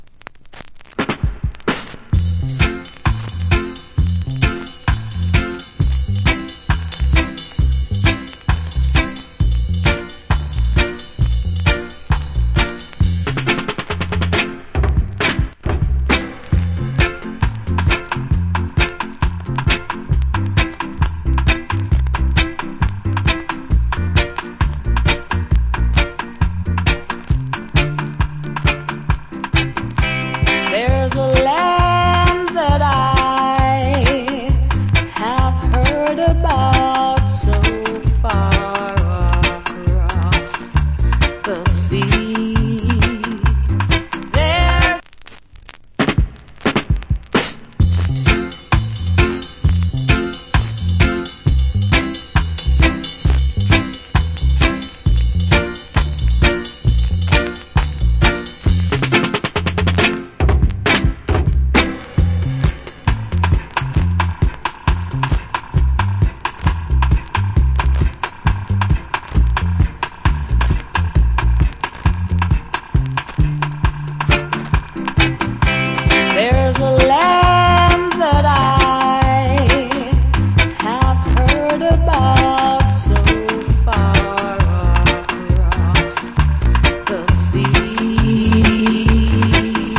Great Female Reggae Vocal.